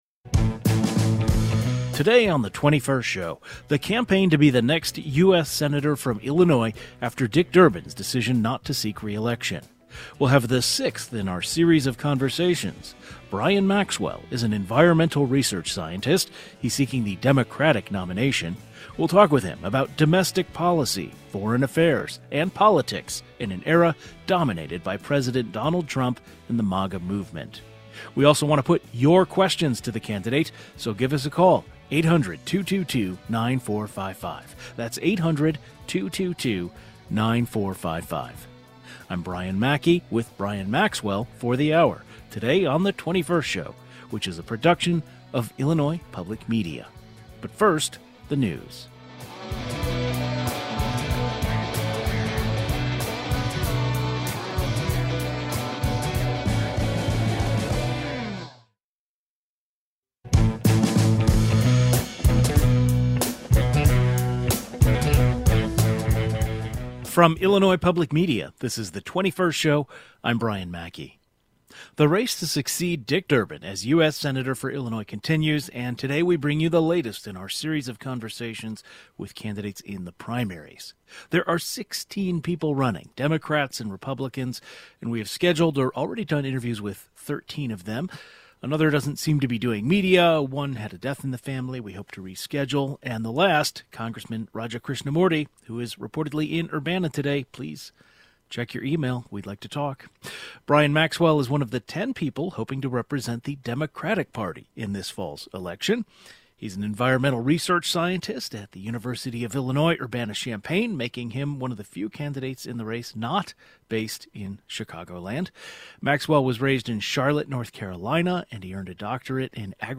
The race to succeed Dick Durbin as U.S. Senator for Illinois continues … and today, we bring you the latest in our series of conversations with candidates in the primaries.